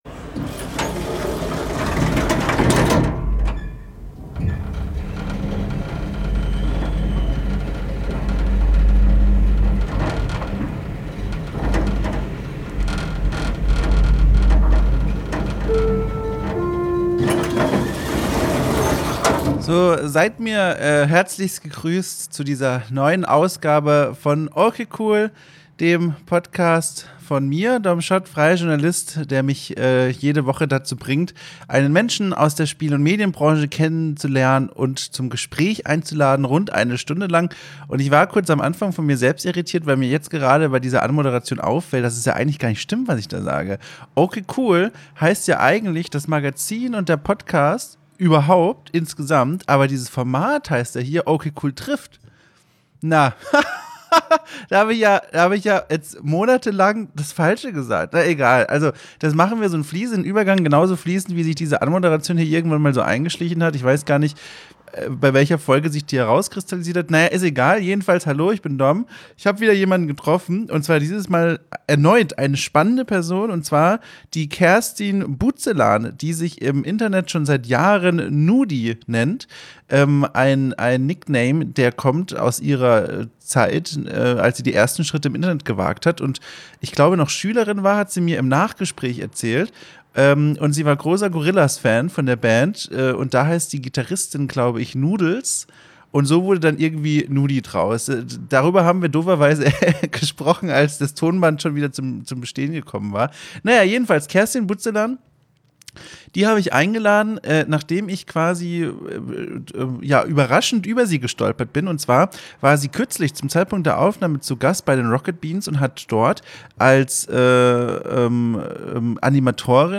Davon erzählt sie im Gespräch